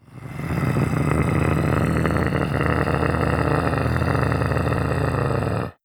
RiftMayhem / Assets / 1-Packs / Audio / Monster Roars / 11.
11. Menacing Growl.wav